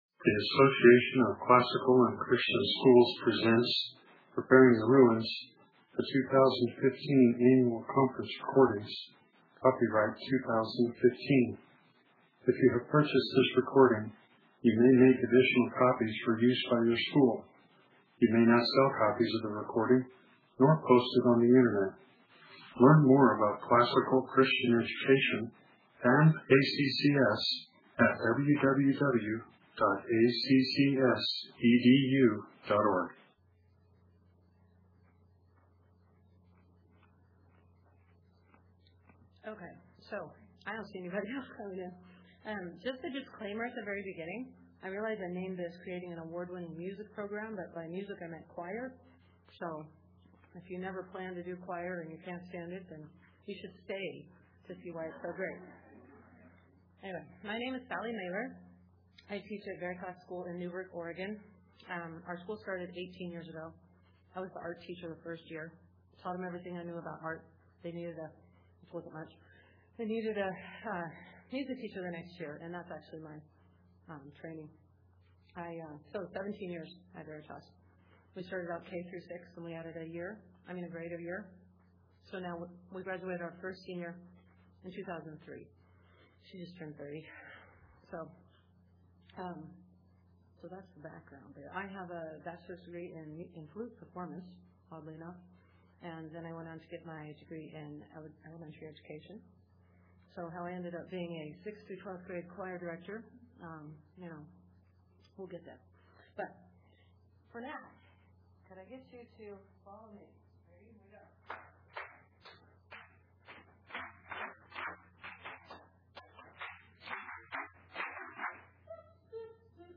2015 Workshop Talk | 1:05:33 | All Grade Levels, Art & Music
Additional Materials The Association of Classical & Christian Schools presents Repairing the Ruins, the ACCS annual conference, copyright ACCS.